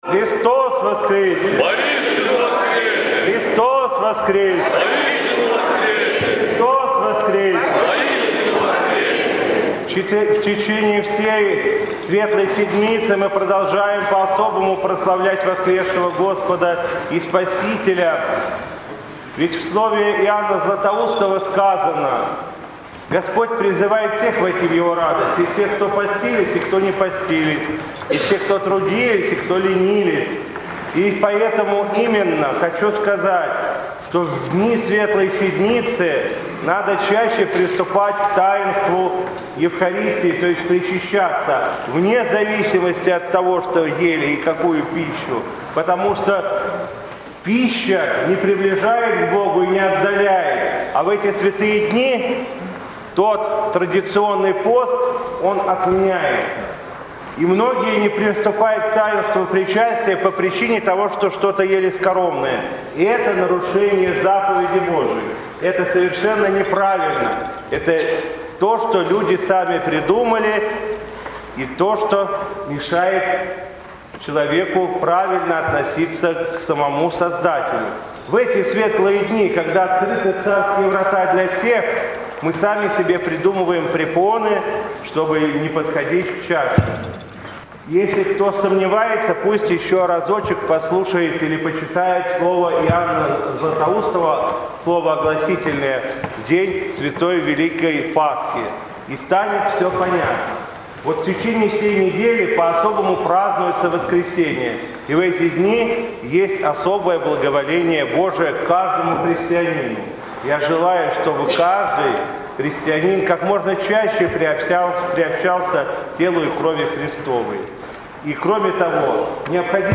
Митрополит Вологодский и Кирилловский Игнатий совершил Пасхальную утреню и Литургию в храме Покрова на Козлёне г.Вологды.
По завершении богослужения митрополит Игнатий поздравил всех присутствующих с праздником Святой Пасхи, обратился к ним с архипастырским словом и вручил им пасхальные крашеные яйца.